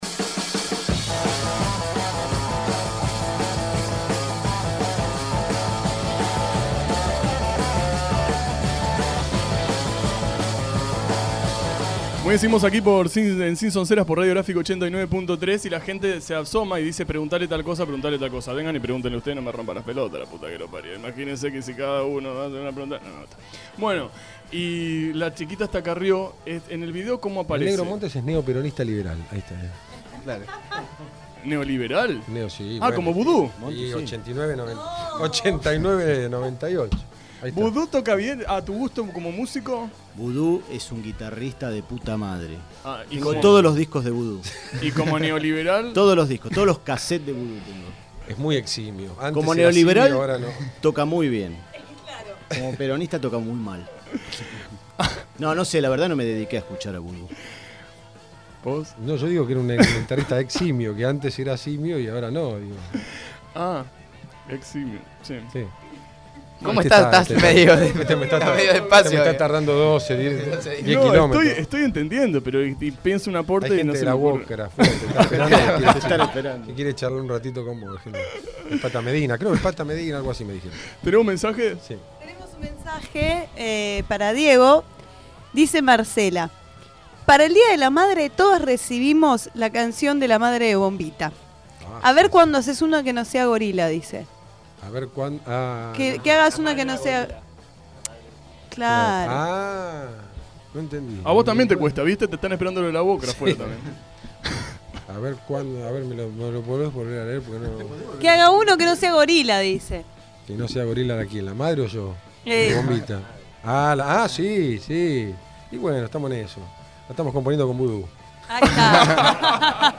En seis fragmentos la entrevista completa para disfrutarla.